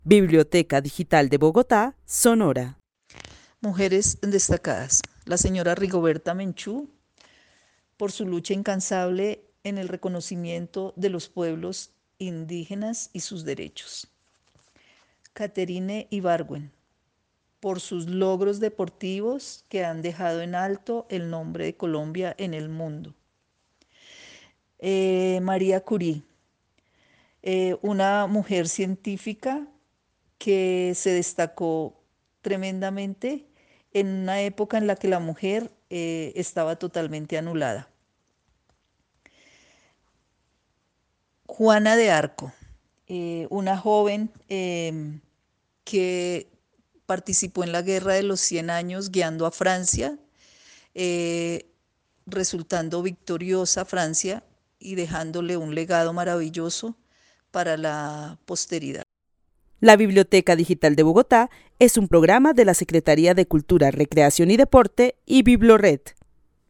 Narración oral de una mujer que vive en la ciudad de Bogotá, quien destaca a las siguientes mujeres: Rigoberta Menchú, por su lucha incansable por el reconocimiento de los pueblos indígenas; Catherine Ibargüen por sus logros deportivos; Marie Curie, científica que se destacó en una época en la que la mujer estaba anulada y Juana de Arco, quien guio a Francia para ganar la guerra. El testimonio fue recolectado en el marco del laboratorio de co-creación "Postales sonoras: mujeres escuchando mujeres" de la línea Cultura Digital e Innovación de la Red Distrital de Bibliotecas Públicas de Bogotá - BibloRed.